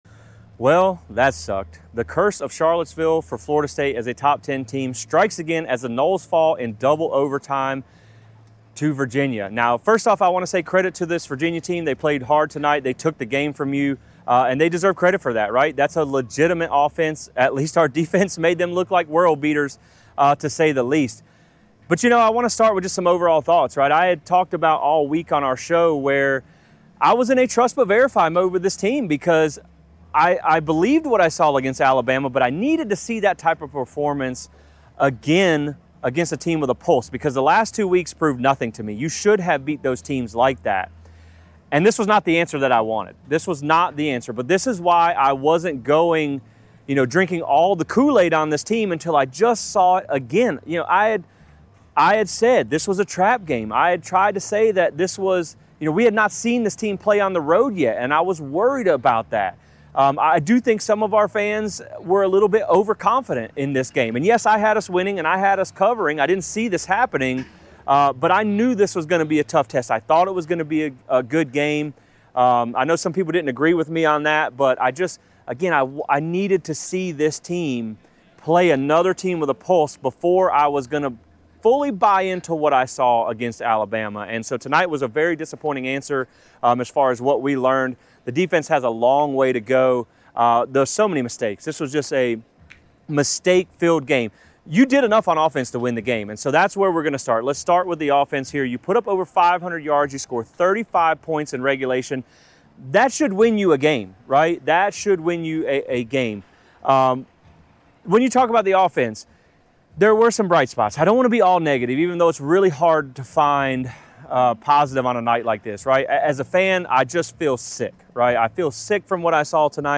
Florida State falls to Virginia in a double overtime thriller in Charlottesville on Friday night. I share my initial thoughts and reaction from Scott Stadium.